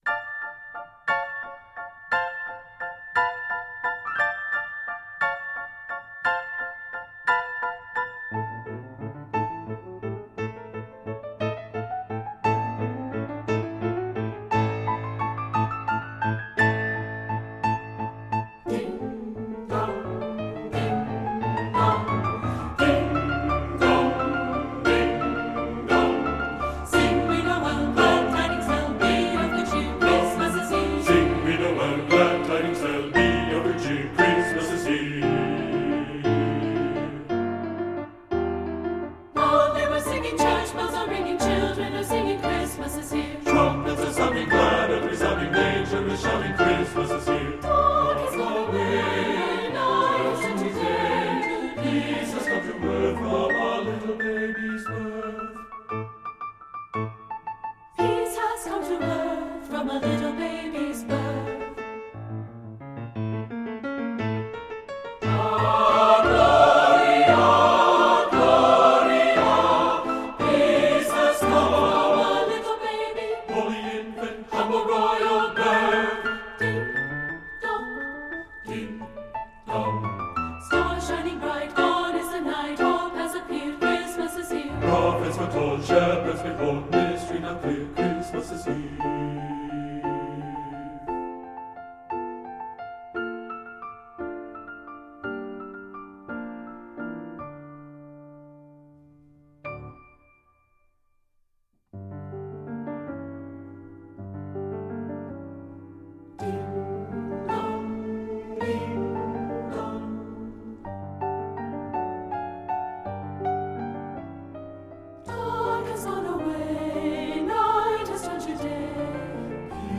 Voicing: SSA and Piano 4 Hands